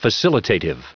Prononciation du mot facilitative en anglais (fichier audio)
Prononciation du mot : facilitative